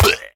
Minecraft Version Minecraft Version snapshot Latest Release | Latest Snapshot snapshot / assets / minecraft / sounds / mob / evocation_illager / death1.ogg Compare With Compare With Latest Release | Latest Snapshot